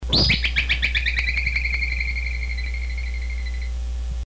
Woodchuck
Voice
A shrill whistle, giving them one of their nicknames, “whistle pig.” They produce this whistle when threatened, and it also serves as a warning call to juvenile woodchucks.
woodchuck-call.mp3